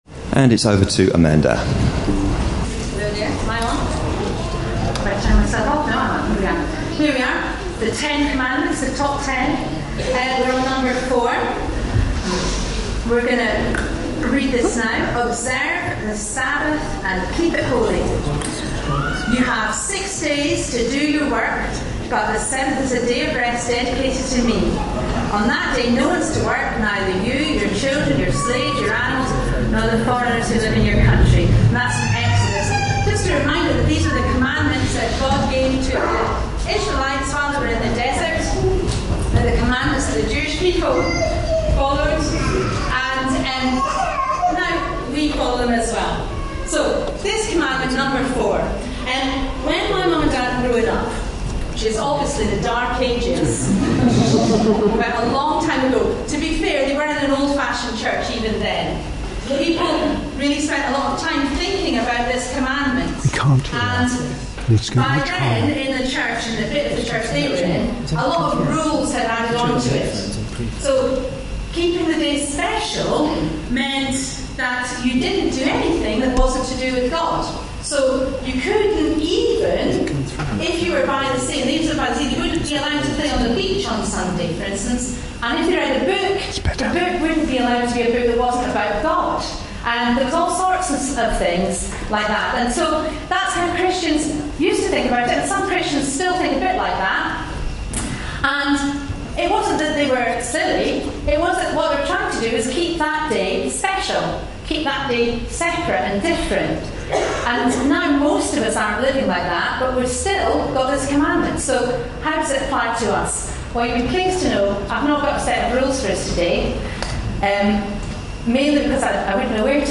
A sermon preached on 16th September, 2012, as part of our Family Service - The Ten Commandments series.
(High background noise at start of recording.)